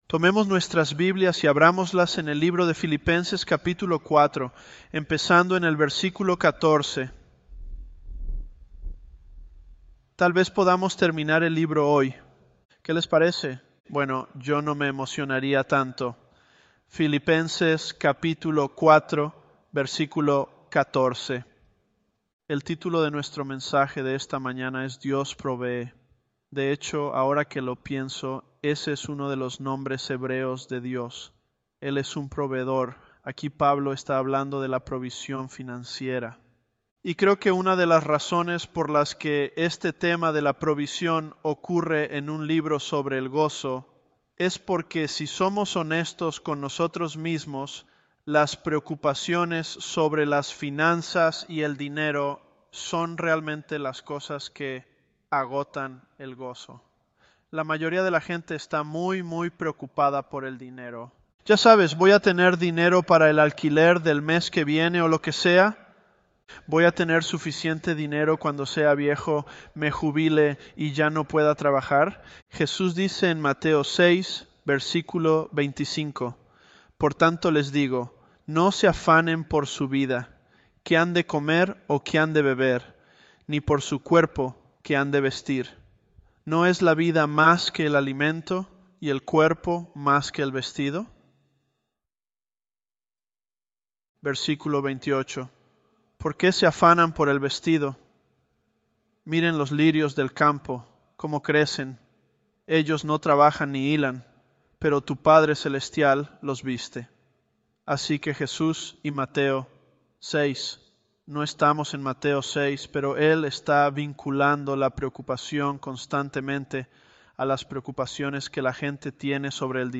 Elevenlabs_Philippians013.mp3